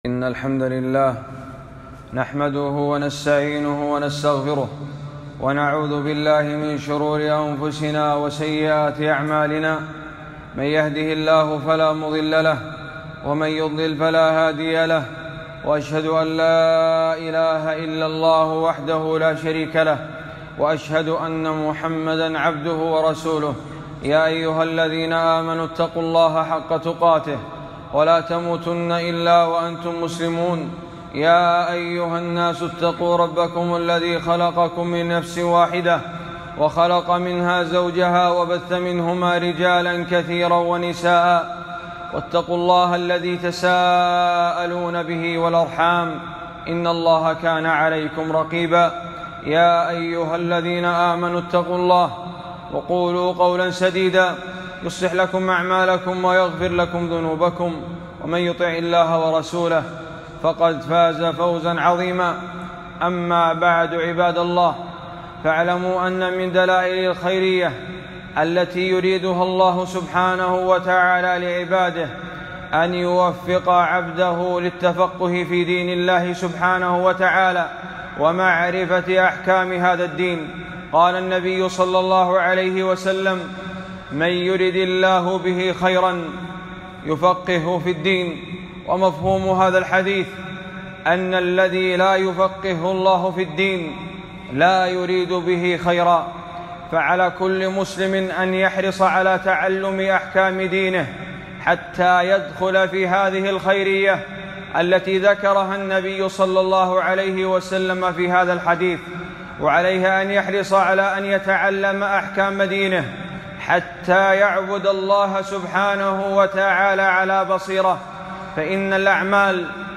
خطبة - مسائل مهمات في فصل الشتاء